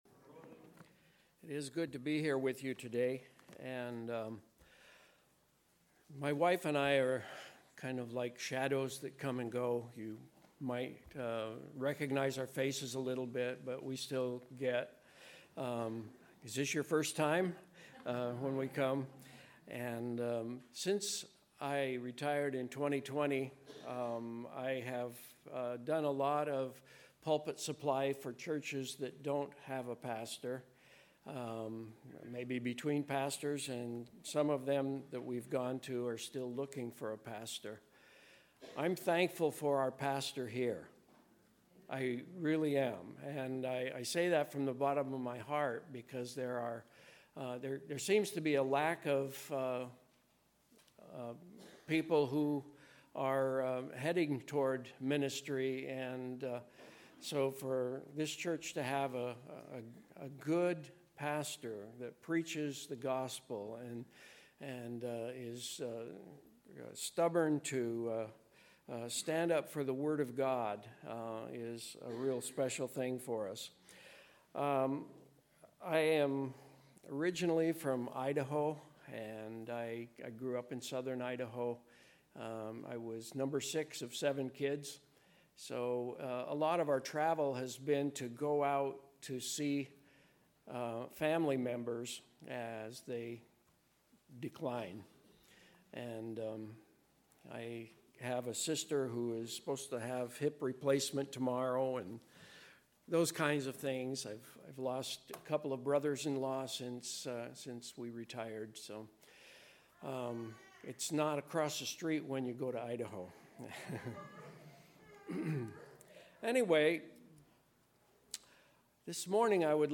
Listen to sermons by our pastor on various topics.
Guest Speaker